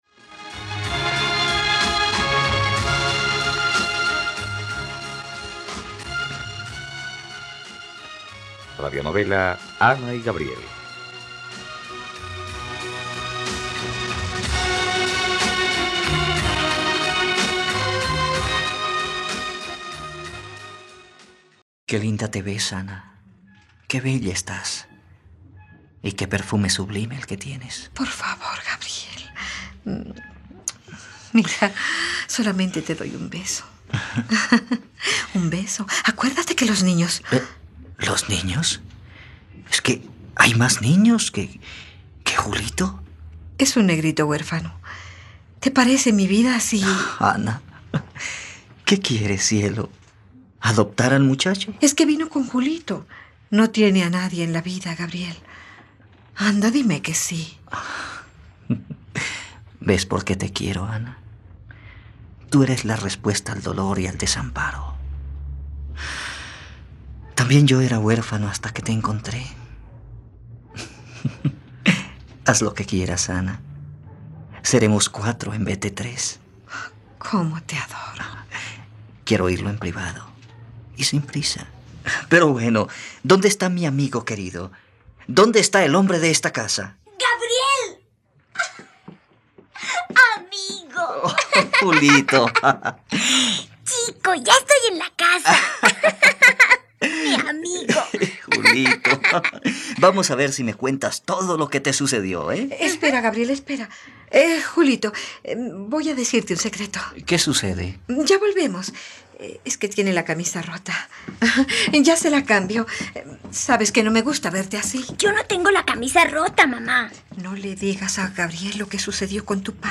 ..Radionovela. Escucha ahora el capítulo 93 de la historia de amor de Ana y Gabriel en la plataforma de streaming de los colombianos: RTVCPlay.